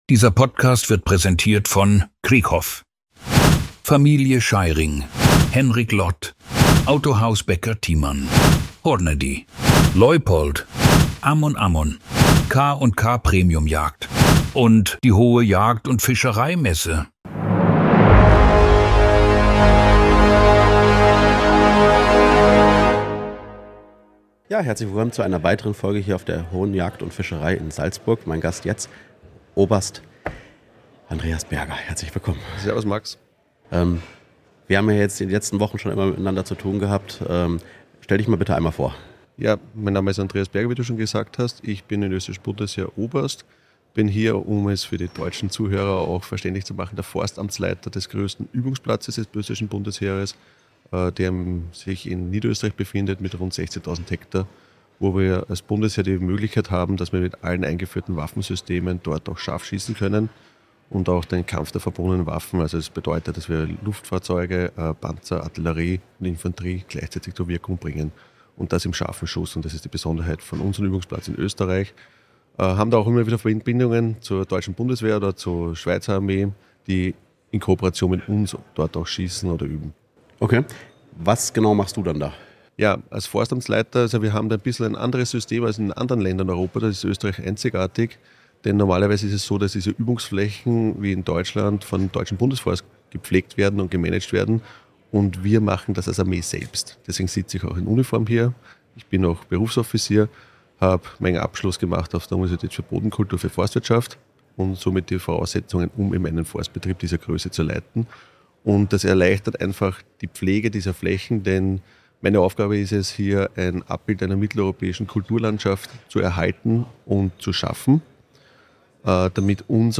Aufgenommen wurde die Episode auf der Hohe Jagd & Fischerei Messe Salzburg.